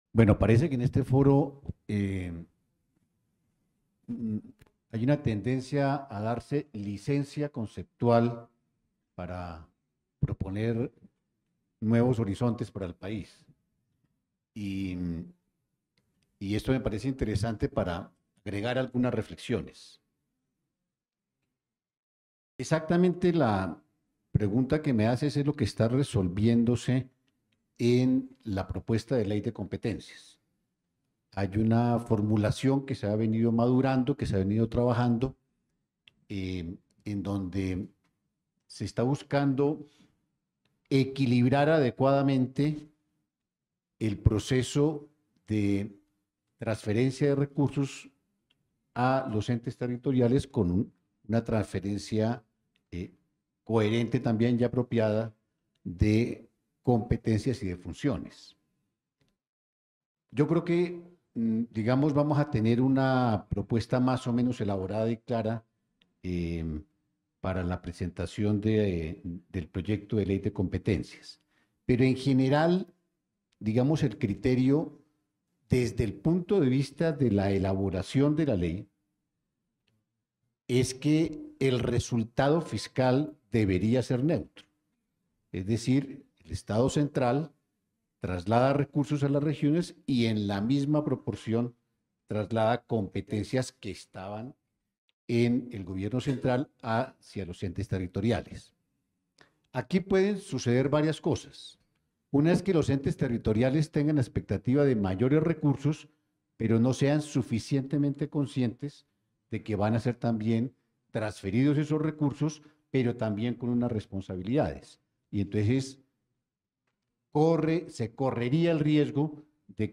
intervención del Ministro | Foro: Descentralización Diferencial y el Control de Recursos Públicos